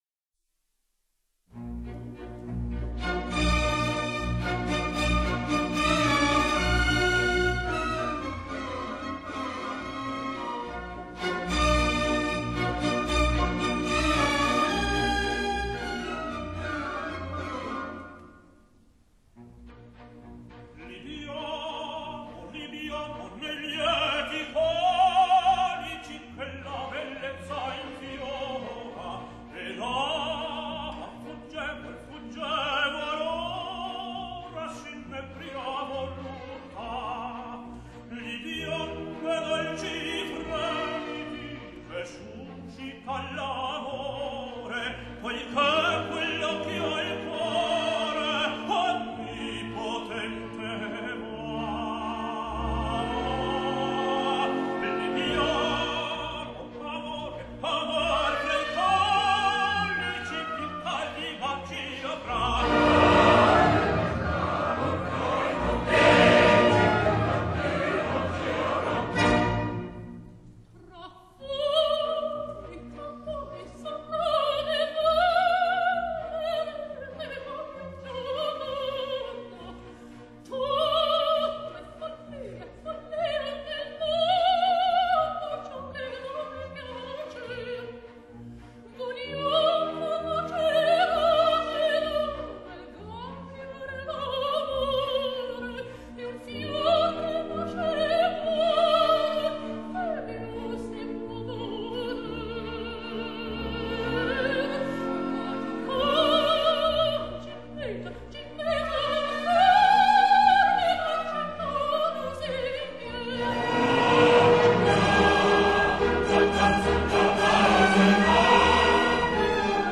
Genre: Opera Arias